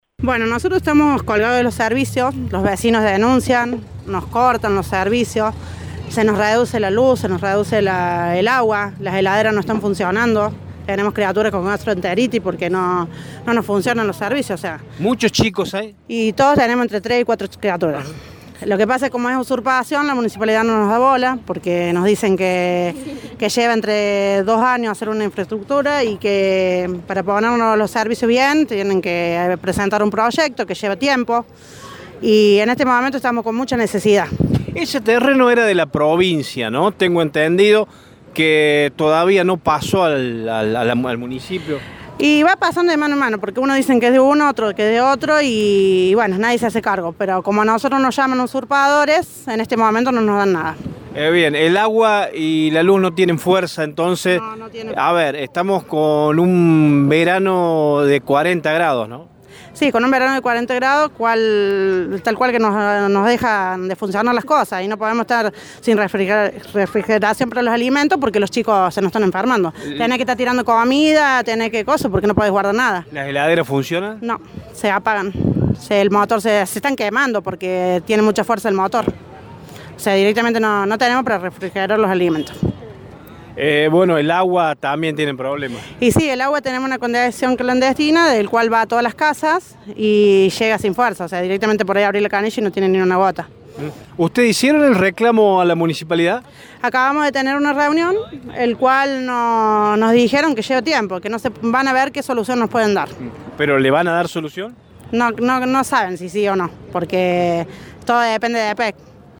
una vecina del barrio, contó la situación a Radio Show.